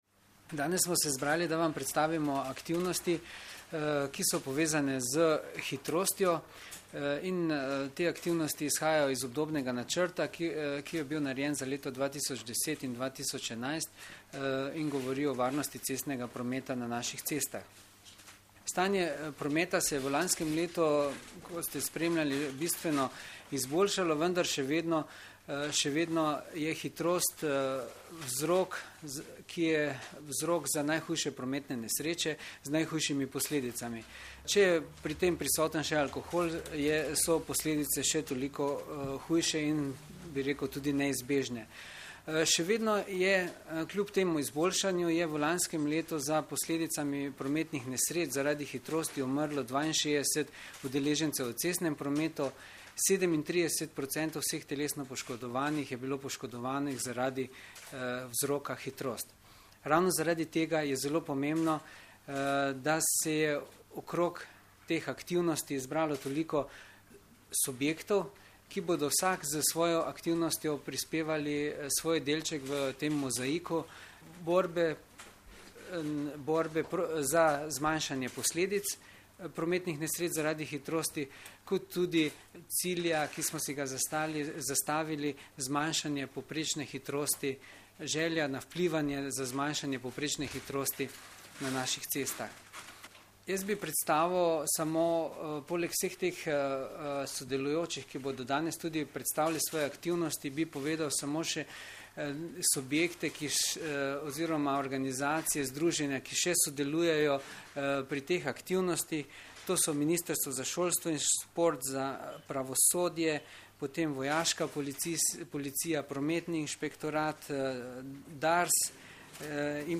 Policija - Policisti začeli s poostrenim nadzorom hitrosti - preventivna kampanja Hvala, ker voziš zmerno - informacija z novinarske konference
Zvočni posnetek izjave